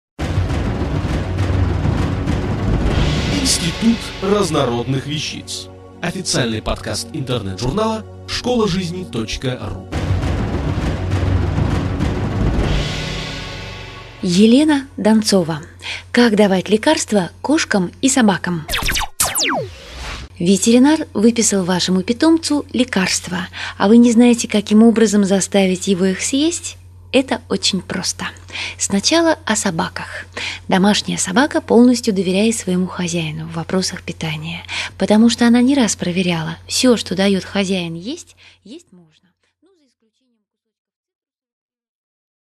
Аудиокнига Как давать лекарства кошкам и собакам?